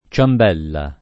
ciambella [ © amb $ lla ] s. f.